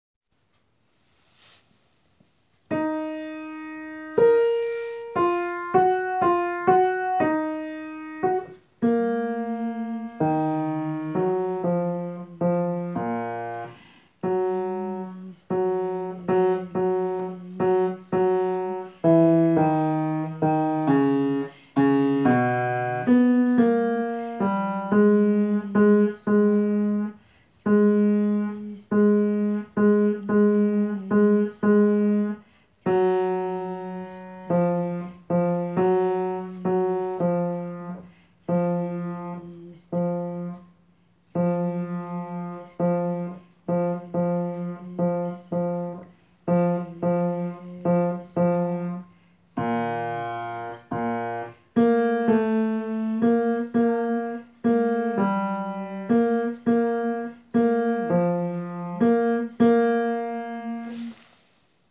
Basse
gounod.christe.basse_.wav